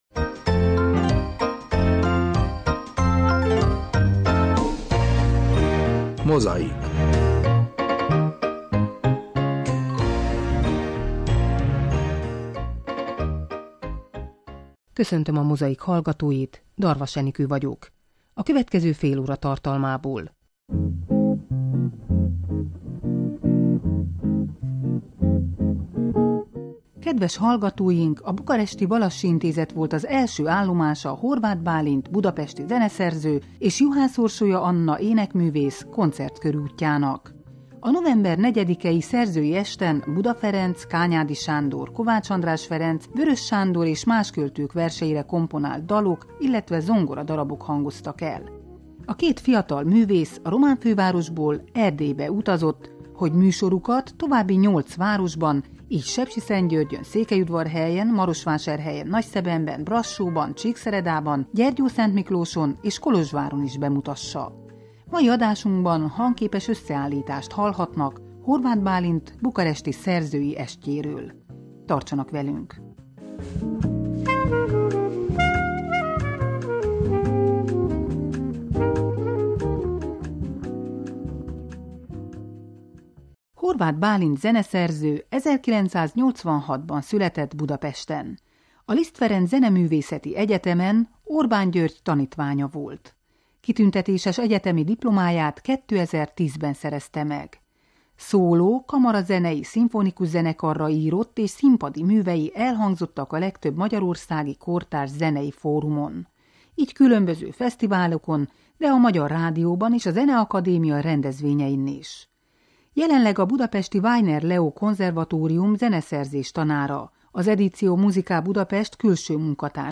A november 4-i szerzői esten Buda Ferenc, Kányádi Sándor, Kovács András Ferenc, Weöres Sándor és más költők verseire komponált dalok, illetve zongoradarabok hangoztak el.
Mai adásunkban hangépes összeállítást hallhatnak